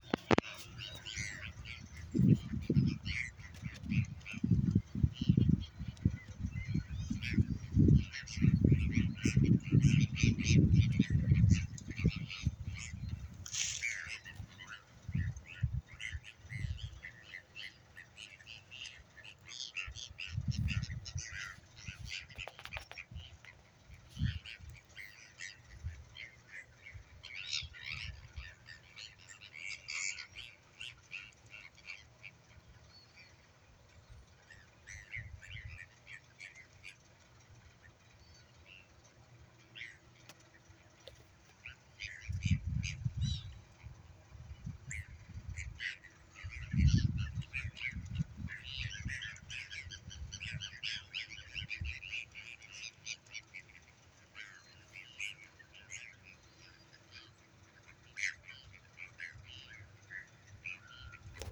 Bonaparte's Gull